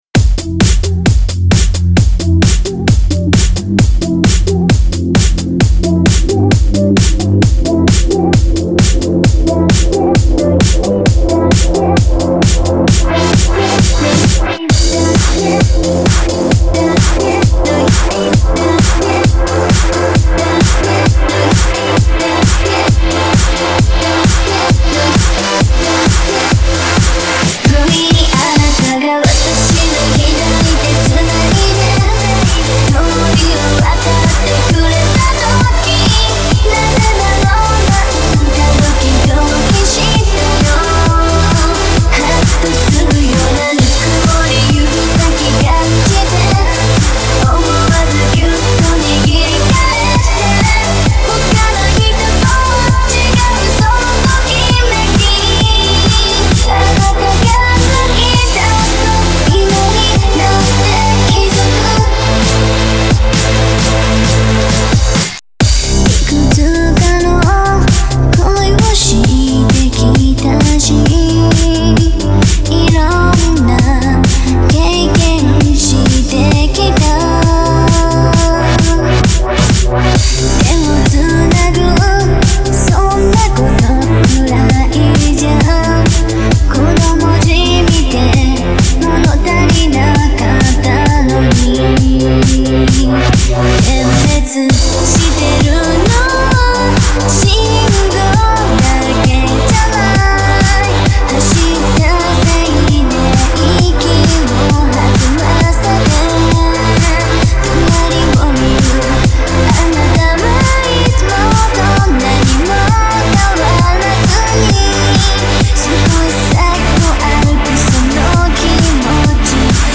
Genre(s): House